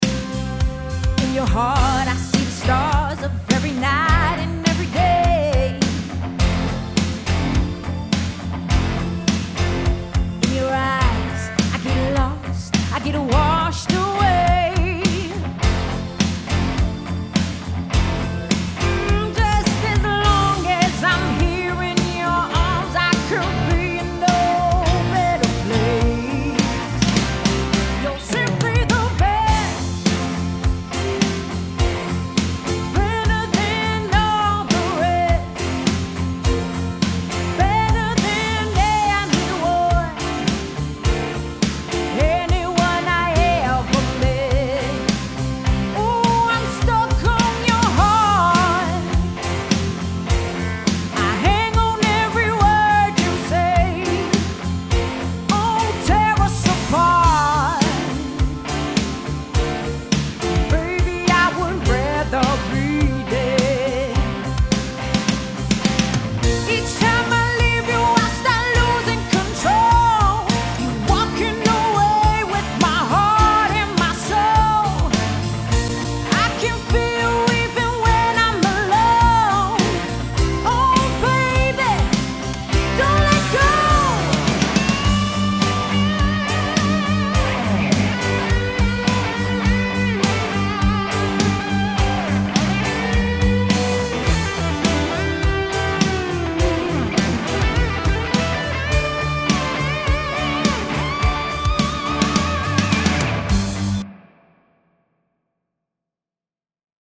Soul, Rock und Pop
LIVE COVER